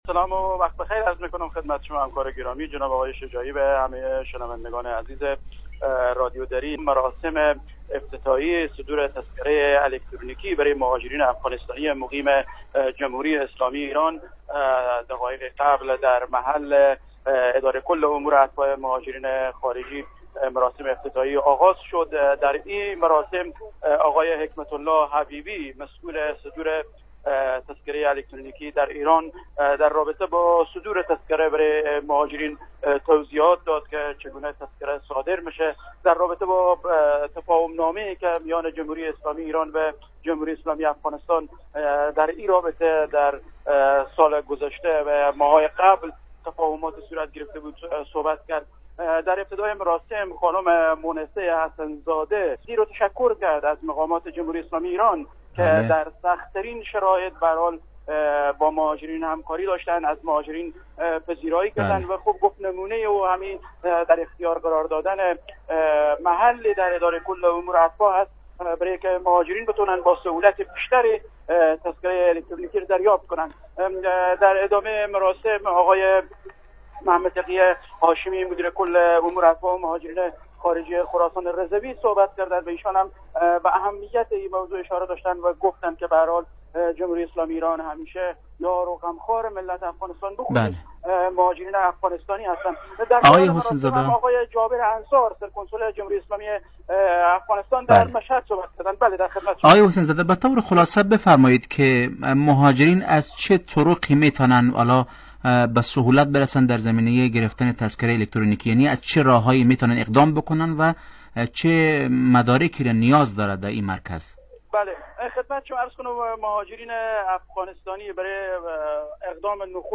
گزارش همکارمان